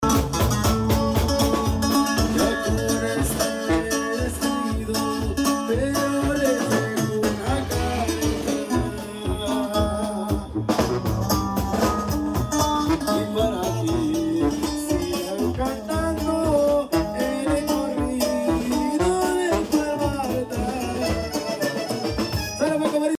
CUANDO ANDAS RONCO SIN VOZ sound effects free download